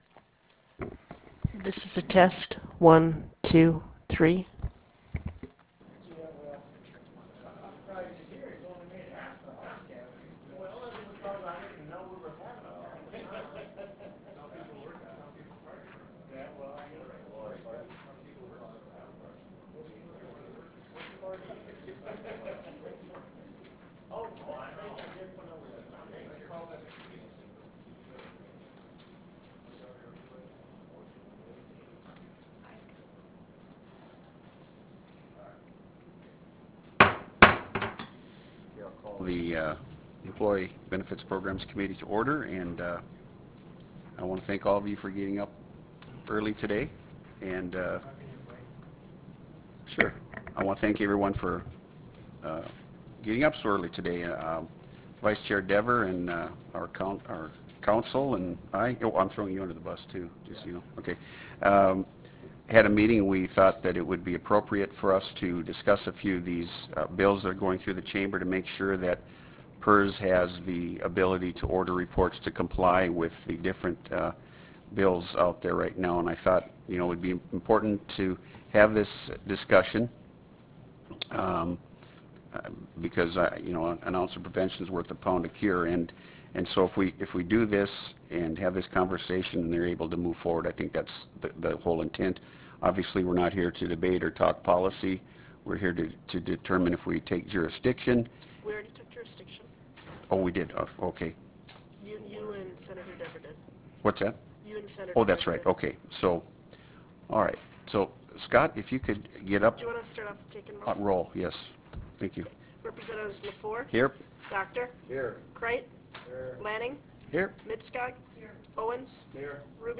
Pioneer Room State Capitol Bismarck, ND United States